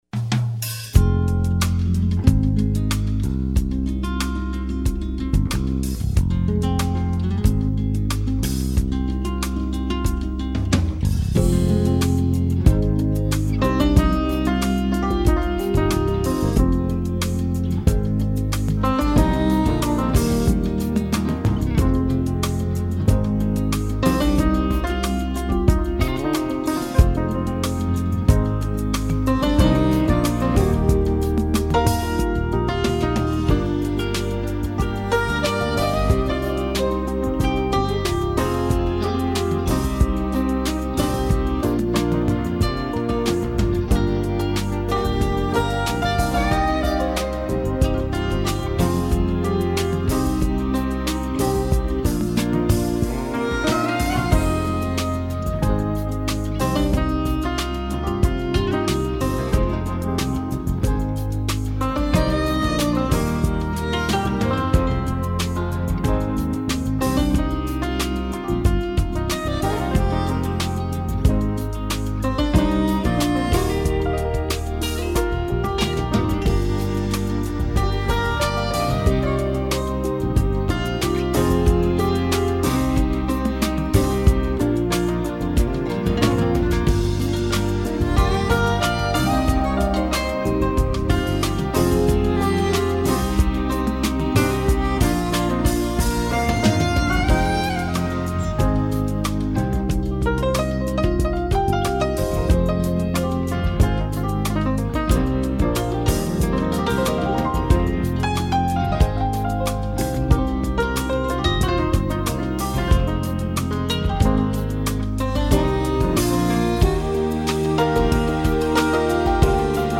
keyboardist
smooth groove